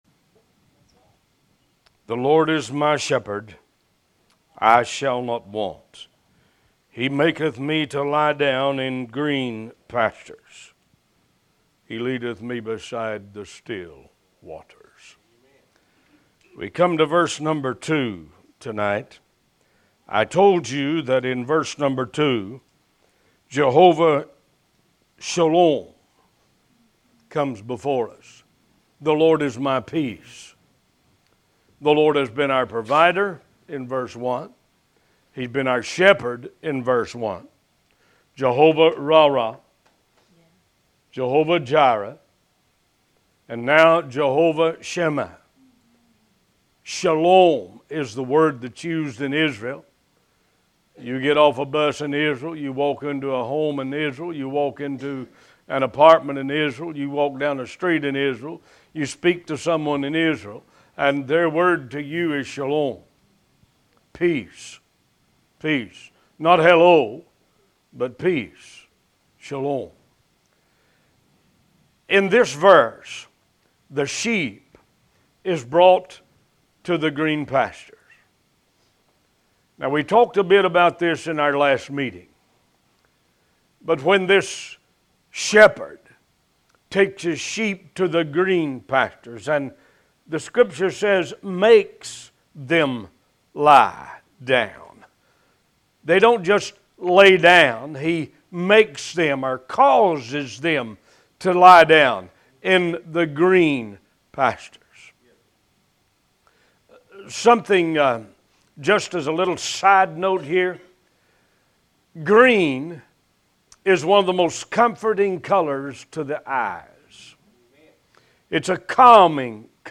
Talk Show Episode, Audio Podcast, One Voice and Psalm 23 series 144 on , show guests , about Psalm 23, categorized as Health & Lifestyle,History,Love & Relationships,Philosophy,Psychology,Christianity,Inspirational,Motivational,Society and Culture